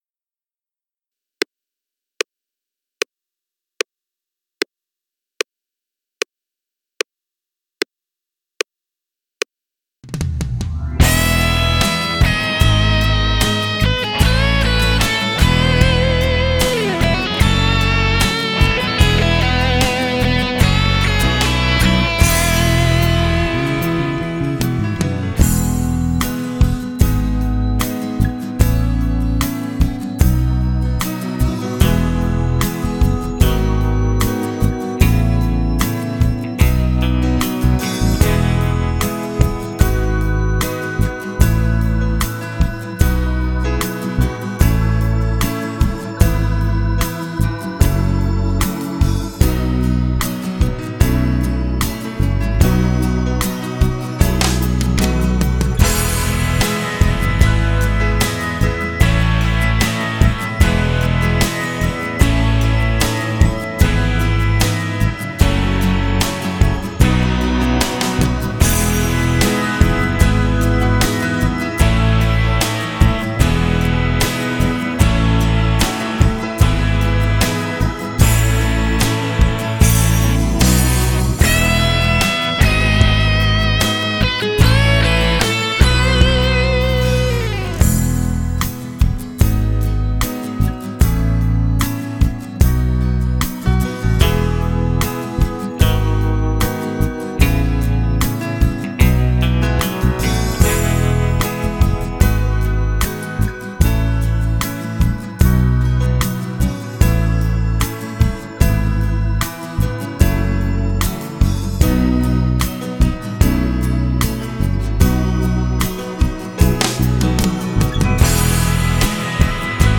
KARAOKE-MP3 HERUNTERLADEN
Gitarrensoli Akkorde Das Paderborn-Lied beginnt in E-Dur .
Karaoke.mp3